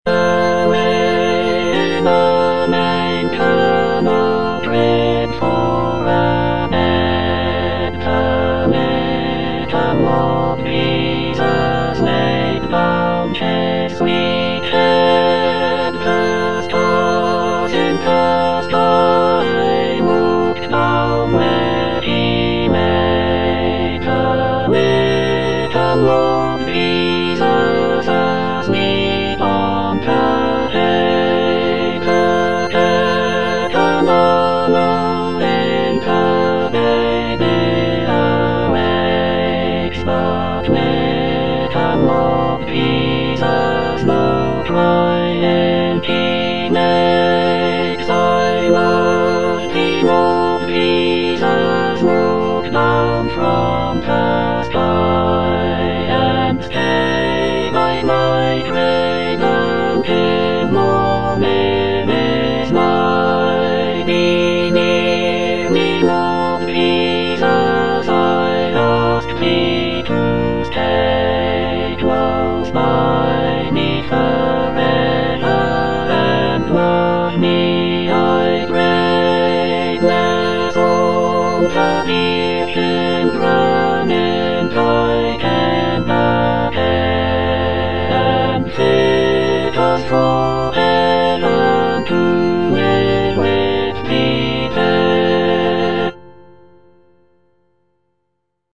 J.R. MURRAY - AWAY IN A MANGER (All voices) Ads stop: auto-stop
"Away in a Manger" is a popular Christmas carol that was first published in 1885.
The gentle and soothing melody of "Away in a Manger" has made it a beloved song for both children and adults during the holiday season.